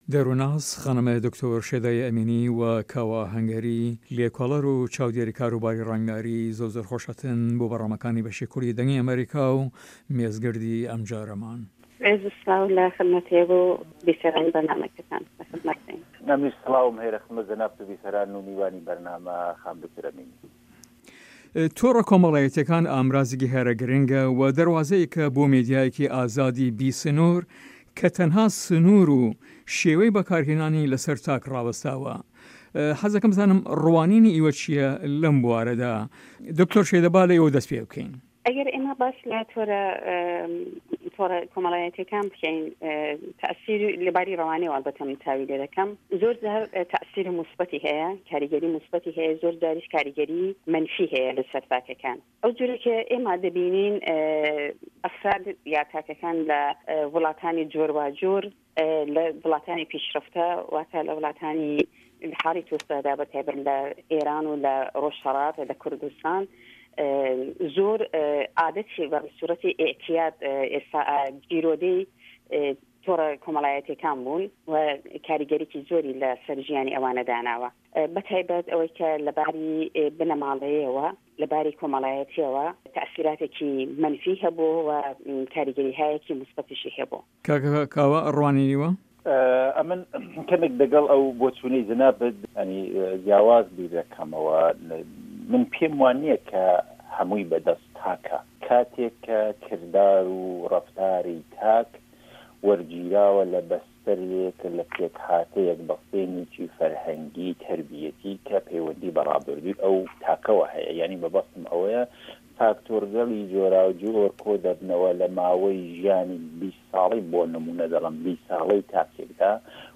Round Table